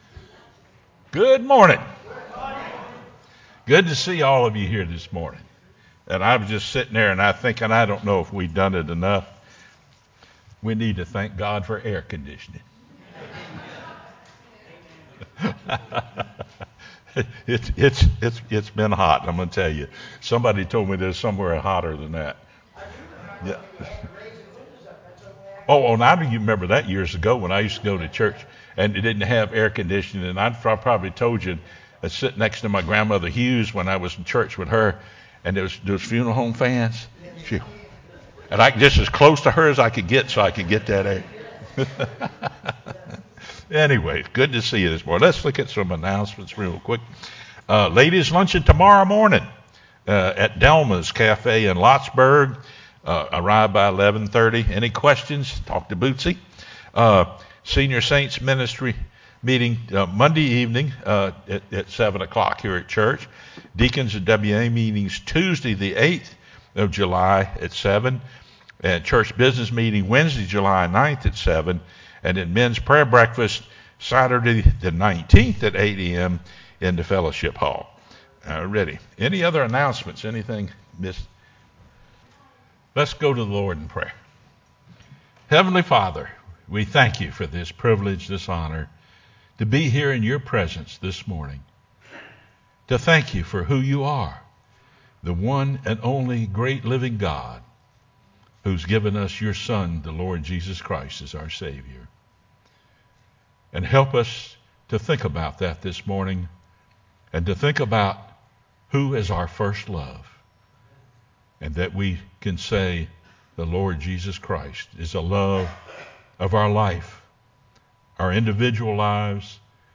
sermonJun29-CD.mp3